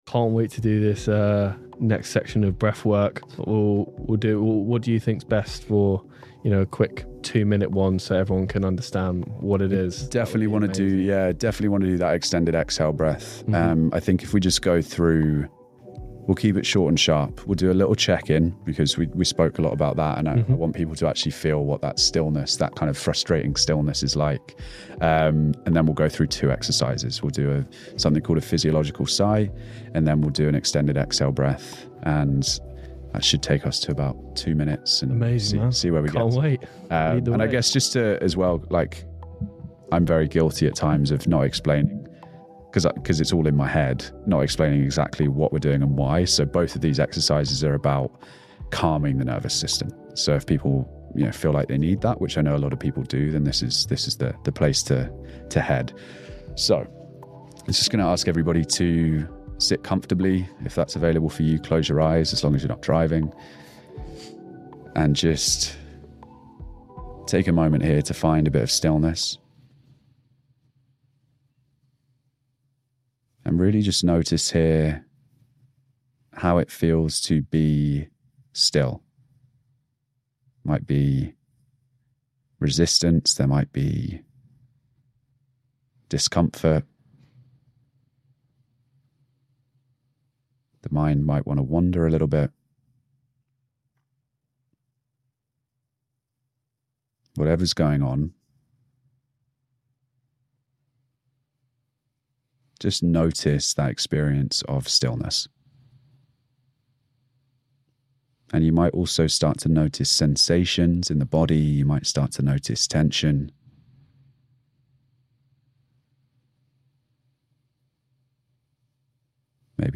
Breathing Exercise To Calm You Down